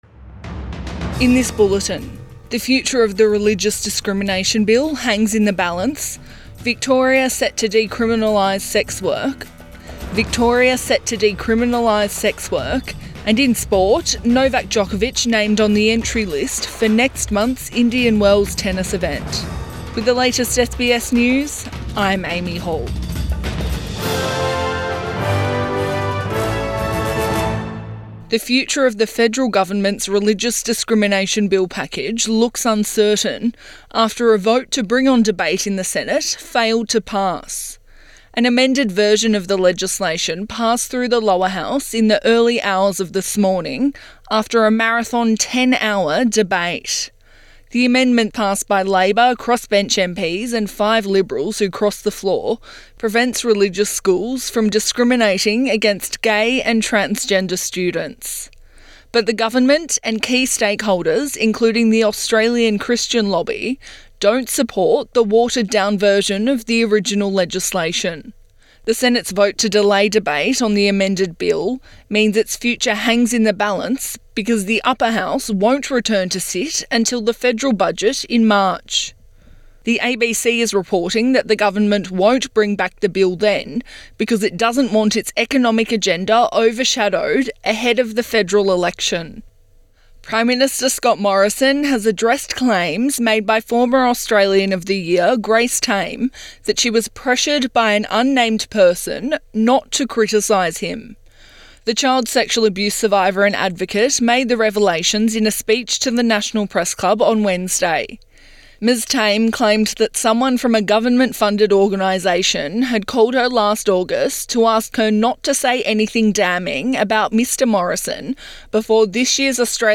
PM bulletin 10 February 2022